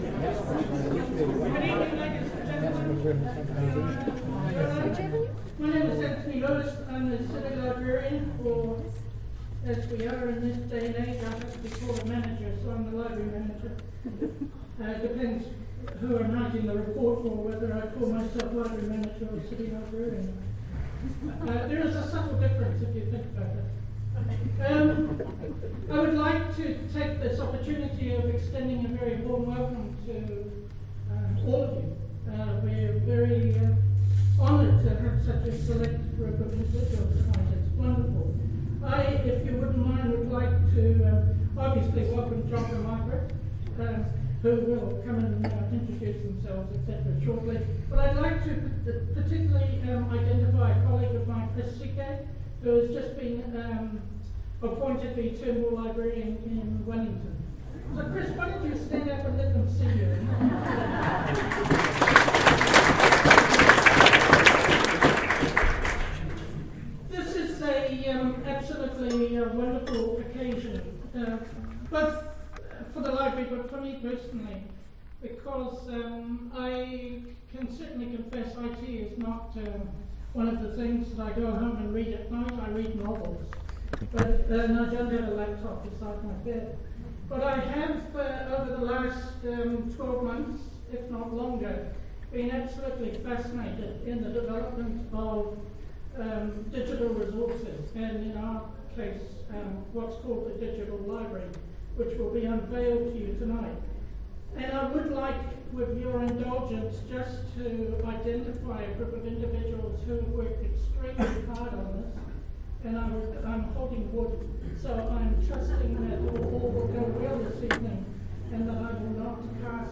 Talk part 1/2 More Info → Description To launch Pataka Ipurangi (Digital Library) the Palmerston North City Library held an evening event in the Sound and Vision Zone. The guest speaker was Dr Jock Phillips the General Editor of Te Ara the 'Online Encyclopedia of New Zealand'. Dr Phillips gives an overview of Te Ara and explored in detail some of the features of the new theme 'The Bush'.
local history talks